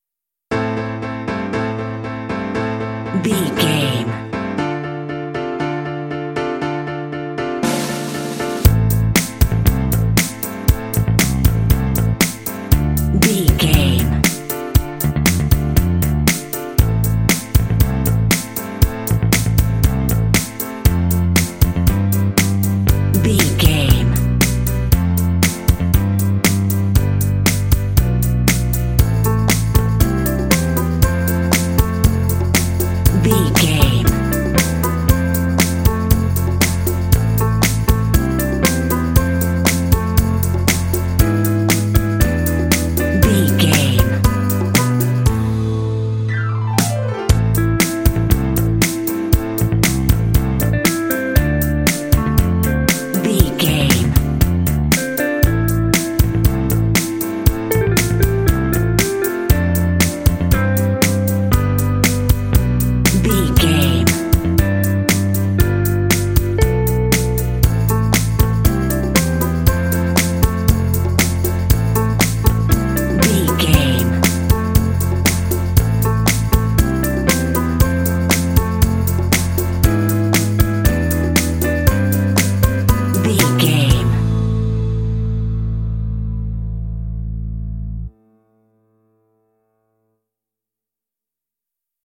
Aeolian/Minor
bouncy
happy
groovy
bright
piano
bass guitar
drums
conga
synthesiser
rock